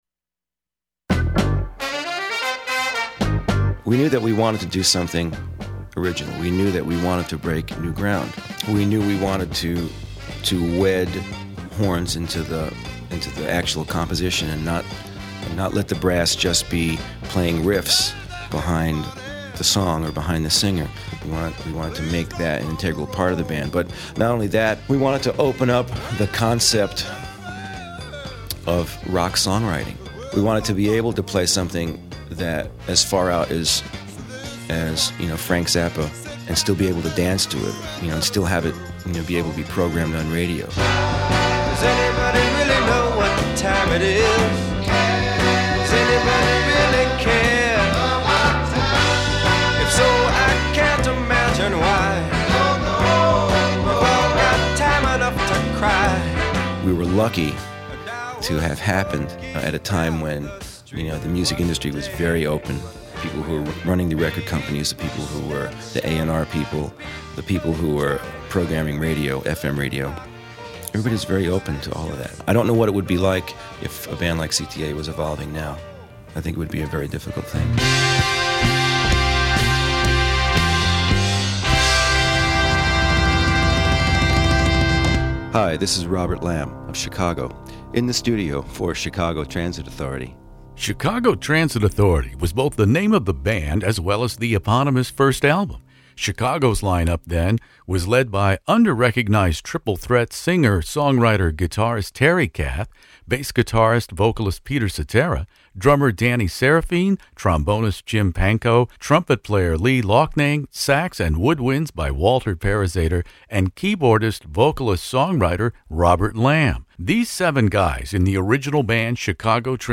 One of three lead singer/ songwriters, keyboard player Robert Lamm is my guest for part one of this classic rock interview.